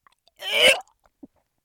vom.mp3